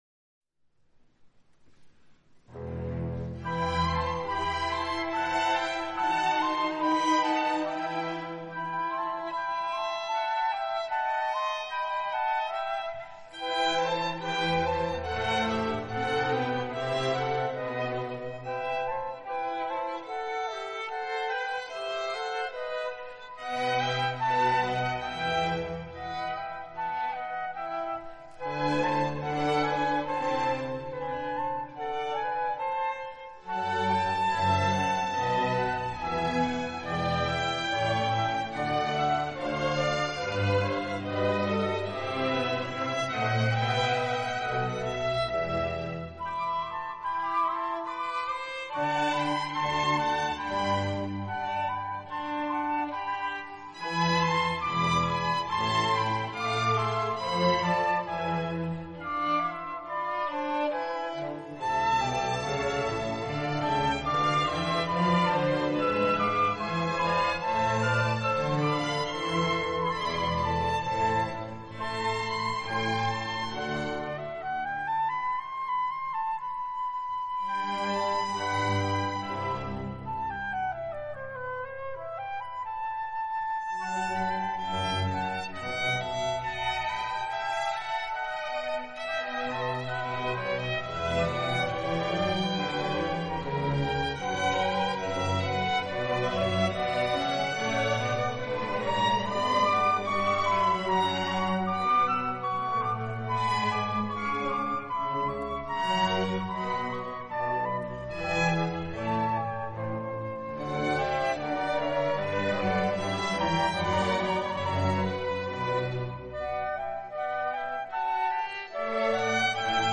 Weihnachtskonzert 2015
Konzertmitschnitt (P-Seminar)